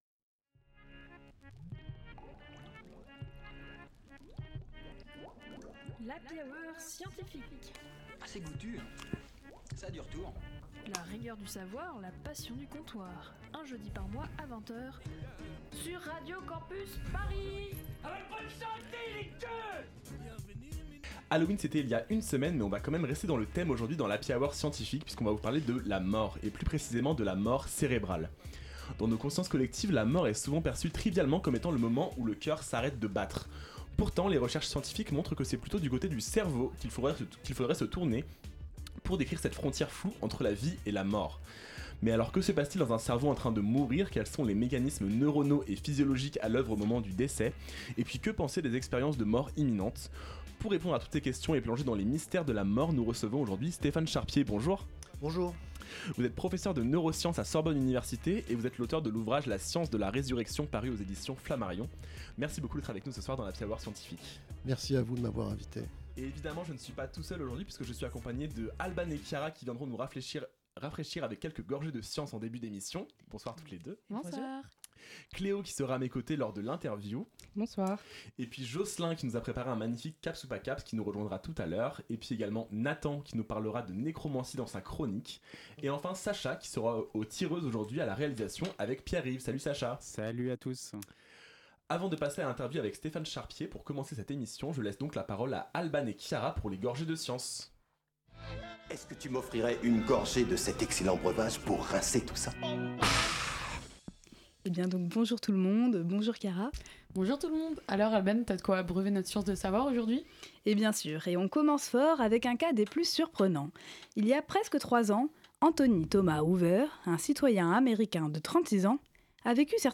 Partager Type Magazine Sciences jeudi 7 novembre 2024 Lire Pause Télécharger Dans nos consciences collectives, la mort est souvent perçue trivialement comme le moment où le cœur s'arrête de battre.